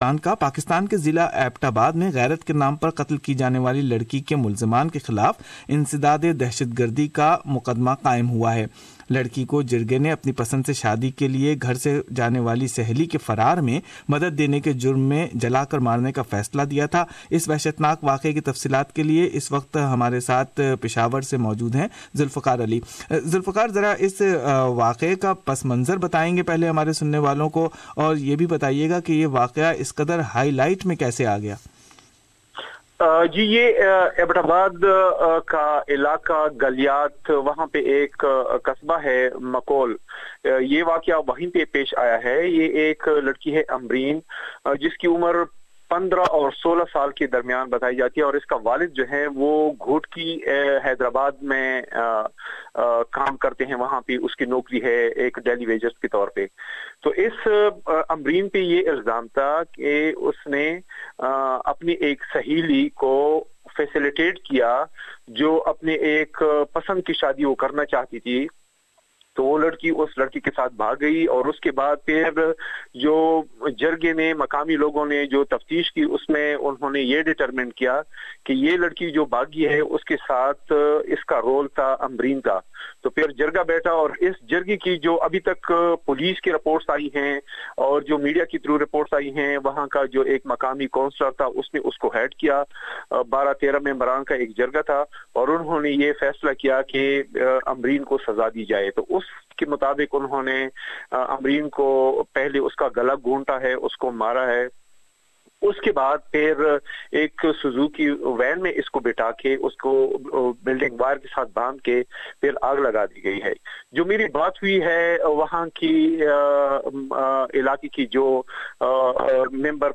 Listen Report from Peshawar.